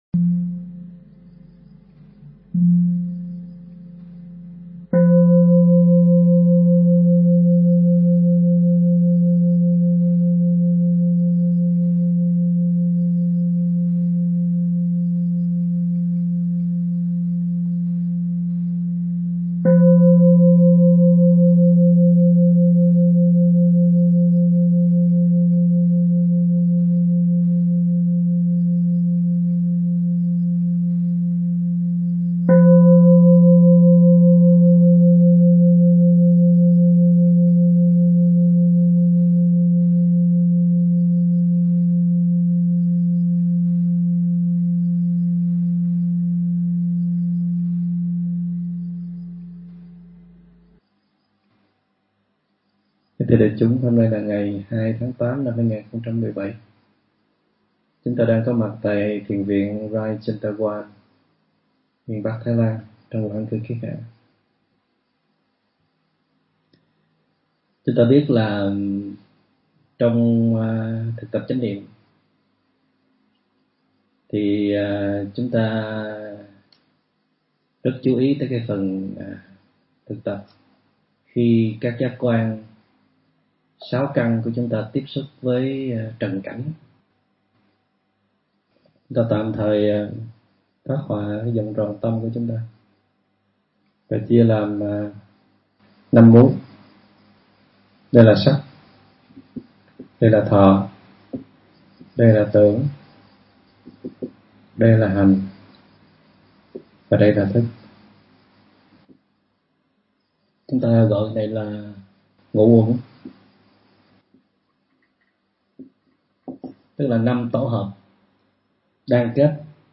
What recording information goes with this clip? tại thiền viện Rai Cherntawan, thuộc miền Bắc, Thái Lan trong mùa an cư kiết hạ